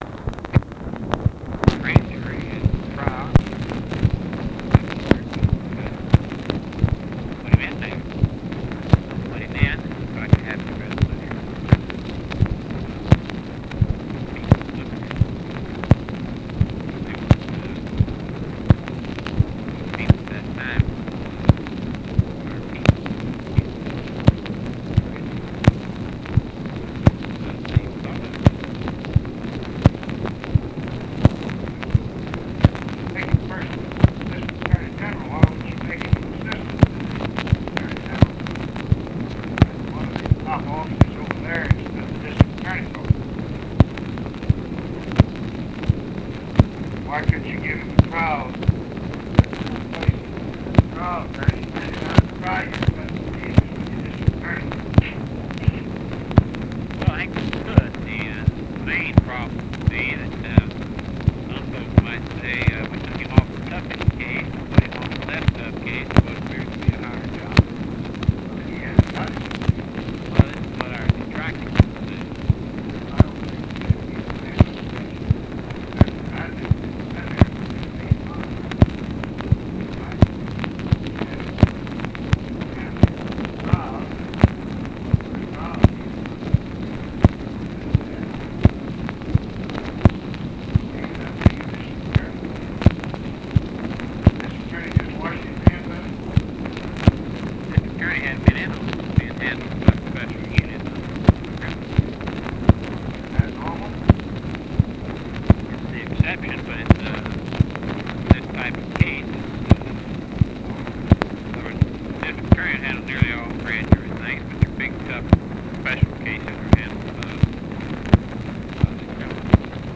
ALMOST INAUDIBLE DISCUSSION OF JUSTICE DEPT OPERATIONS?
Secret White House Tapes